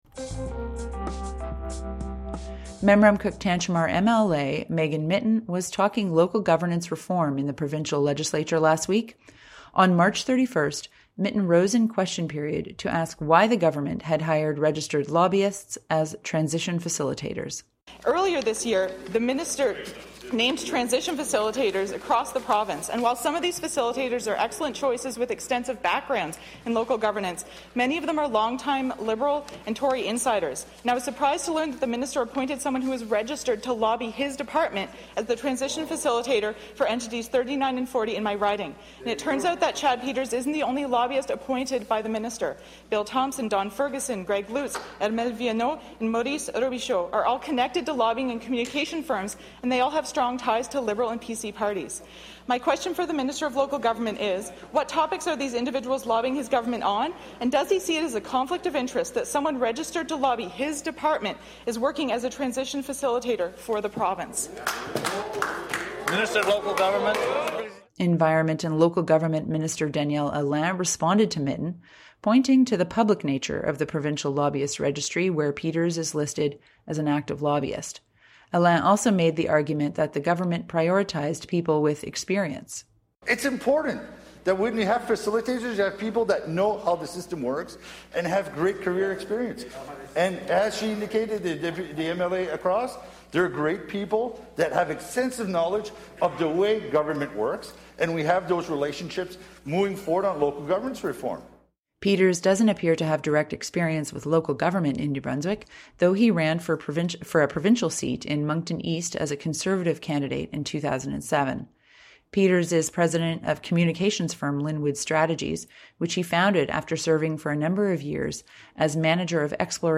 Memramcook-Tantramar MLA Megan Mitton was talking local governance reform in the provincial legislature last week.
CHMA listens in on the exchange between the Green and Conservative representatives: